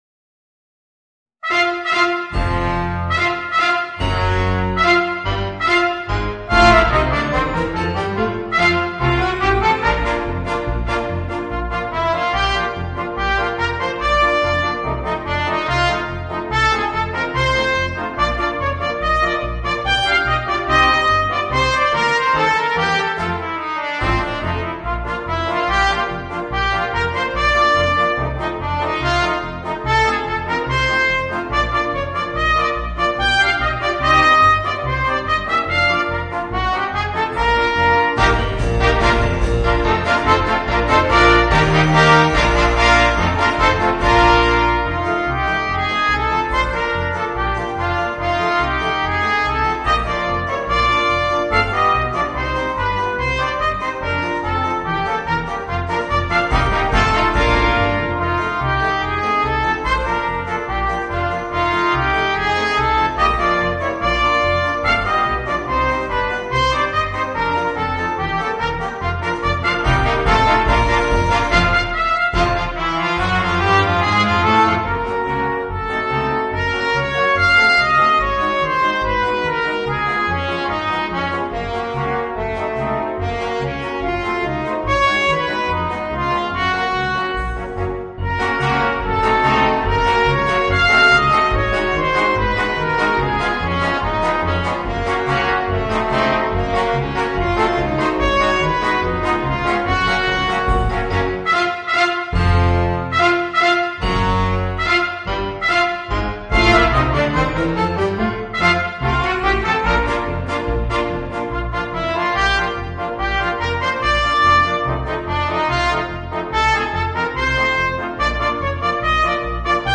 Voicing: 2 Trumpets, Horn, Trombone, Tuba and Drums